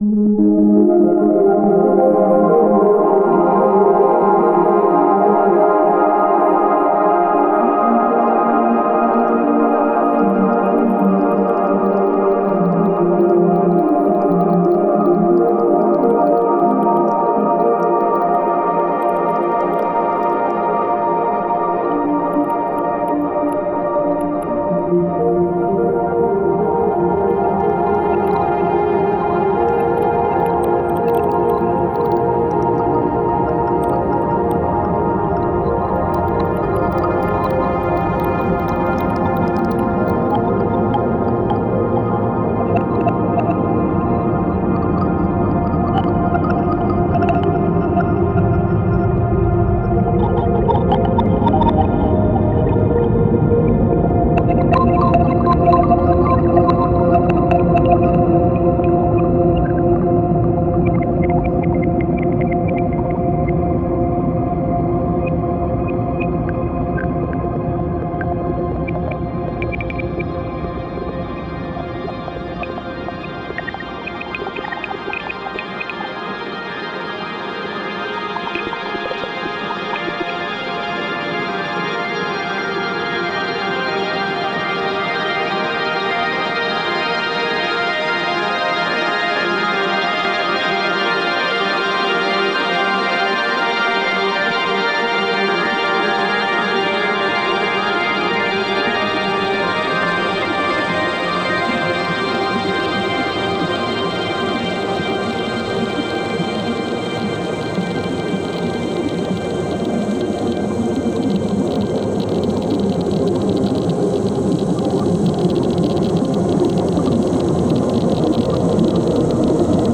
アンビエント系では特に使い勝手も良く、 重宝するサンプルパックです。
Genre:Ambient